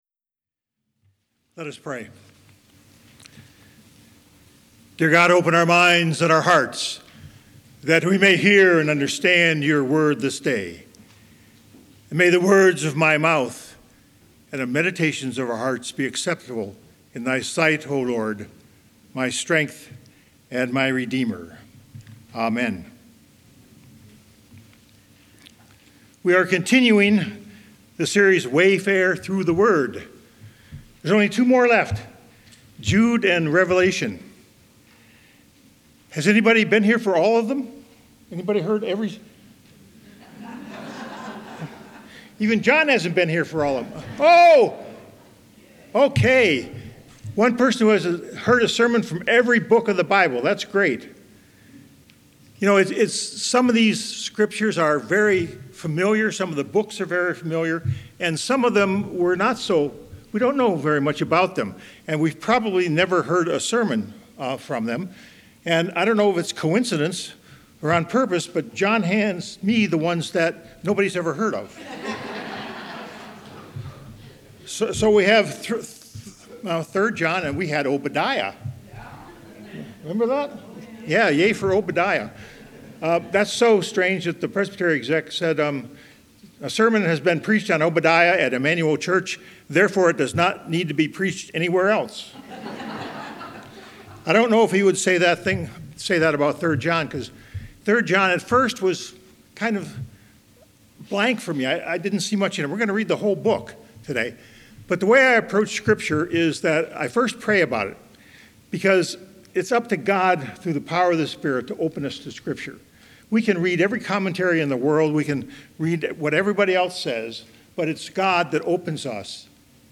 (There is no transcript for this sermon.